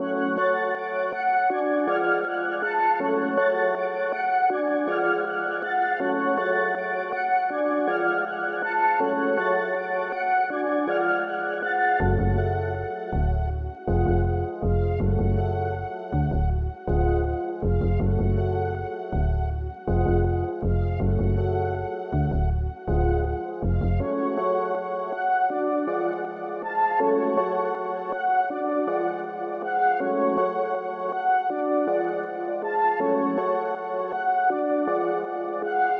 描述：调 C 阿拉伯语，bpm 160
Tag: 160 bpm Trap Loops Synth Loops 6.06 MB wav Key : C FL Studio